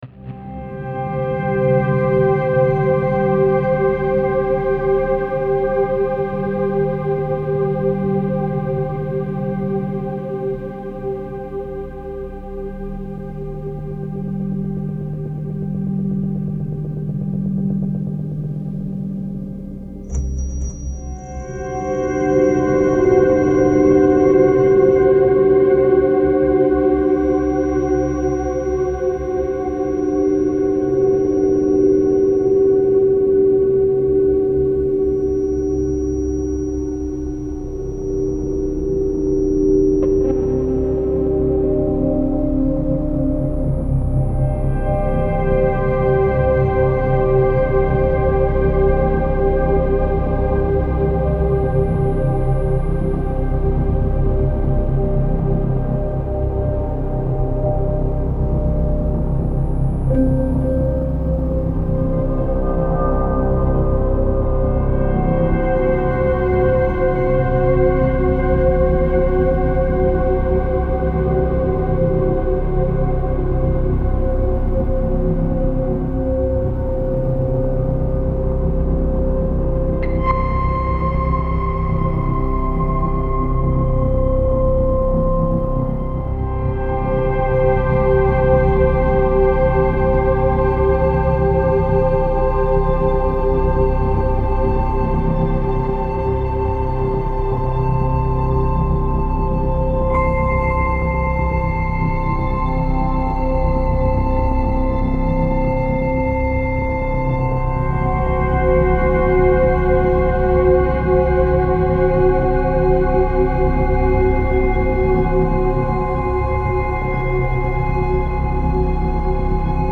Style Style Ambient, Soundtrack
Mood Mood Dark, Mysterious
Featured Featured Synth